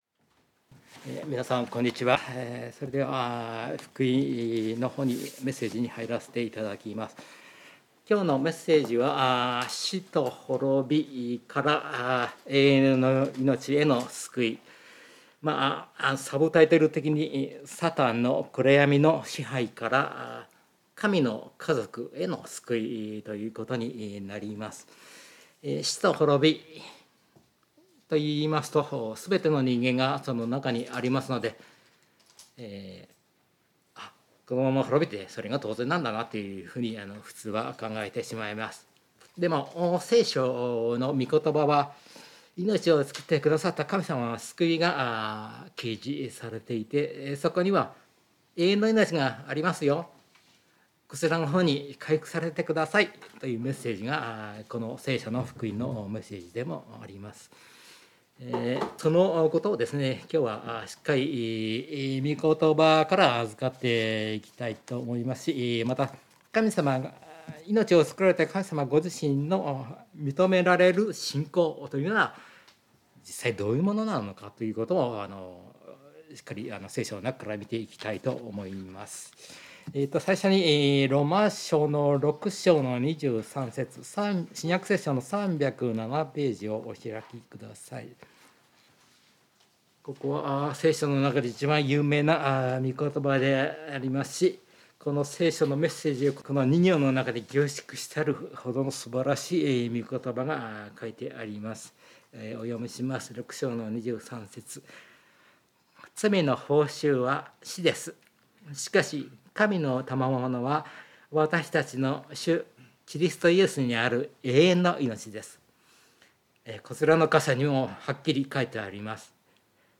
聖書メッセージ No.242